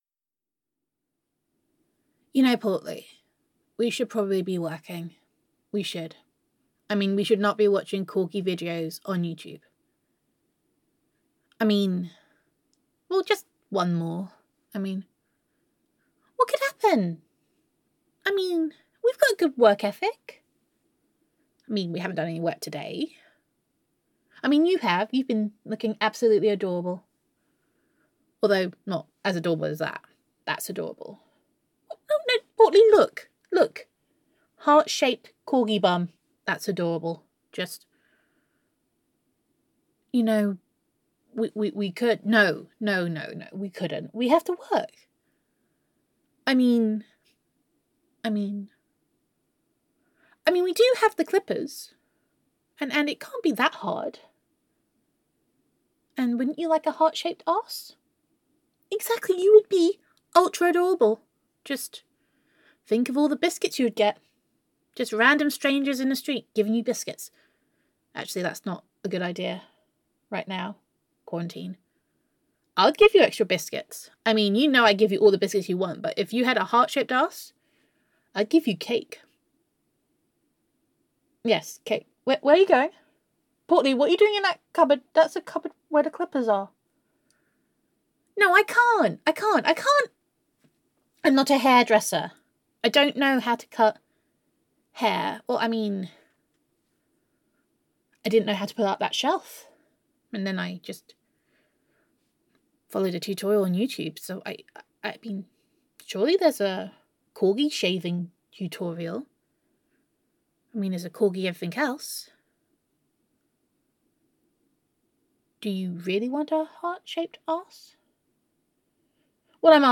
[F4A] Day Five - What Could Go Wrong? [Girlfriend Roleplay][Self Quarantine][Domestic Bliss][Gender Neutral][Self-Quarantine With Honey]